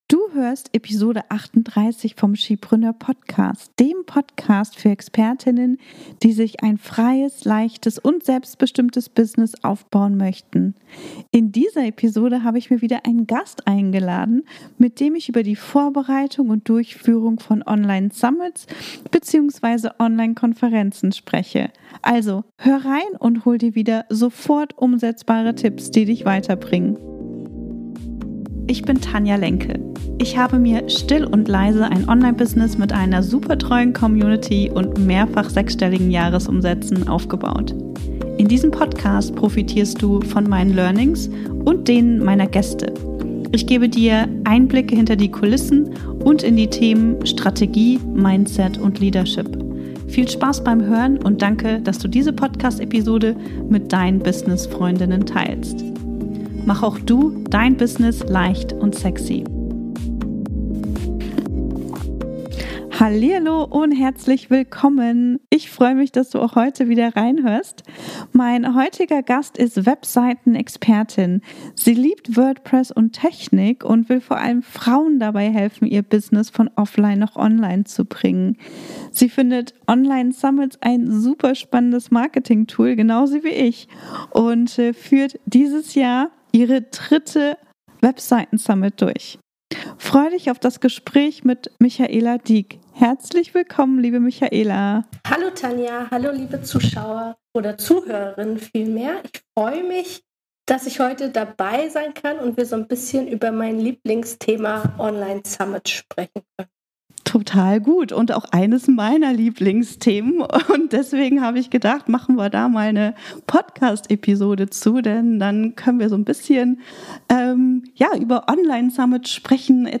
Eine Online-Summit kann ein Reichweiten-Booster, ein Sichtbarkeitsschubser, ein Weg zur Vernetzung, zur Umsatzsteigerung und zur Darstellung deiner Expertise sein.  In dieser Folge habe ich mir wieder einen Gast eingeladen, mit dem ich über die Vorbereitung und Durchführung von Online-Summits bzw. Online-Konferenzen spreche.